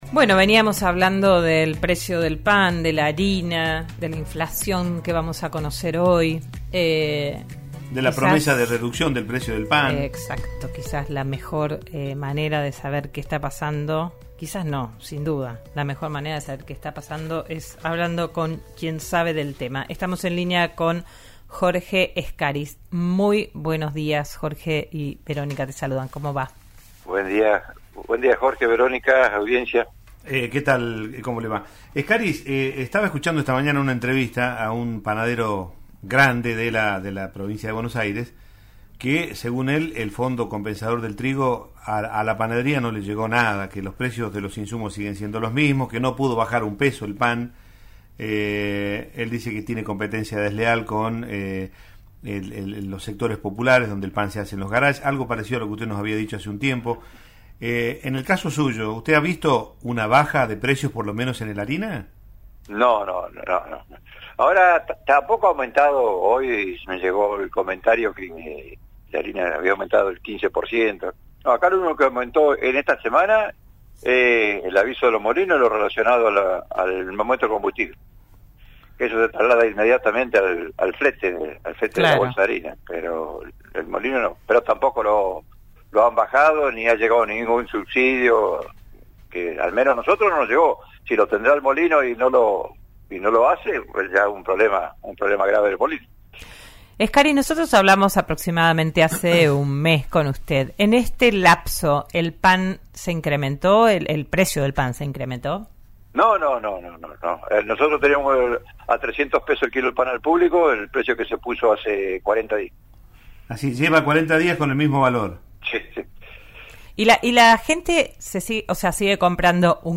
En diálogo con RN Radio lo consultaron sobre cuál es el rubro que más sintió la caída de las ventas por la crisis y respondió que es el de «las masas finas, es lo primero que se resiente, porque la torta frita, por ejemplo, depende del día. SI hay un día frío, gris, lluvioso se vende más allá de lo que cueste».